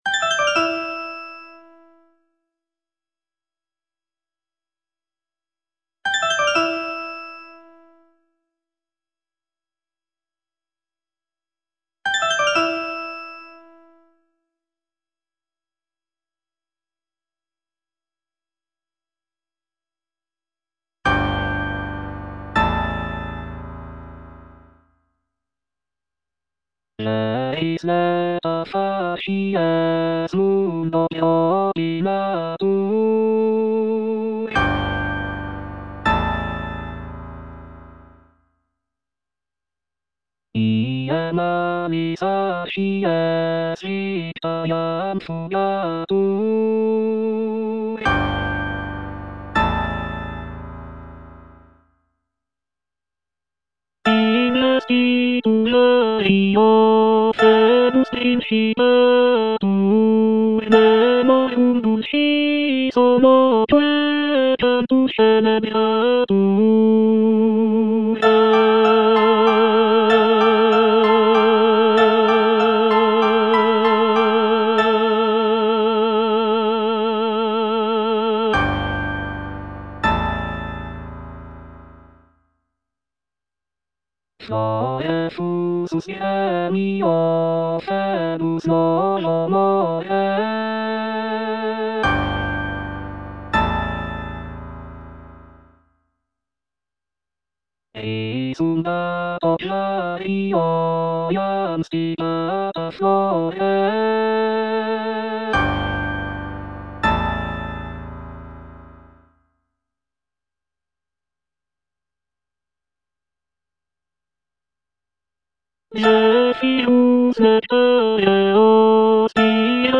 Tenor (Emphasised voice and other voices) Ads stop
scenic cantata